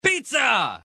Звуки пиццы
8. Тот же самый но тише